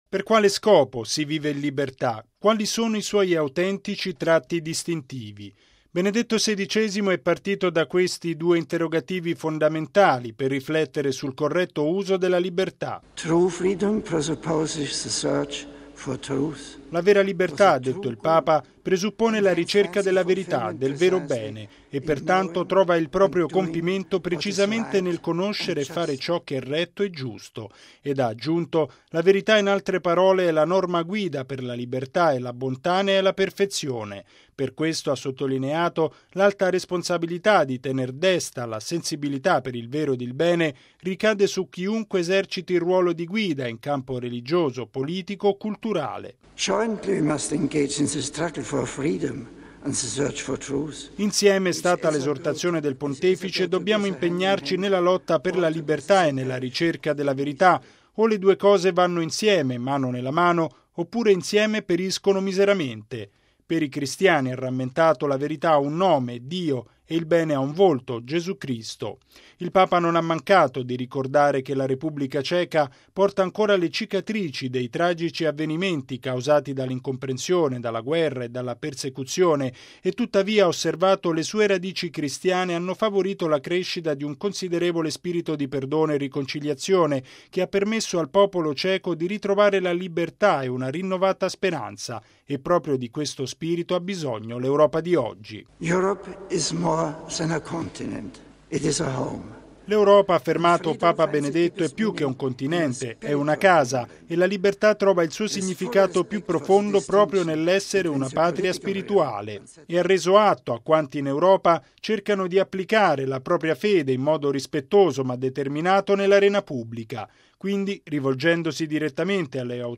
◊   Un'appassionata riflessione sul senso della verità e della libertà: è quella che Benedetto XVI ha svolto ieri nell’incontro con le autorità politiche ceche e con il corpo diplomatico, nella Sala Spagnola del Castello di Praga.
Il servizio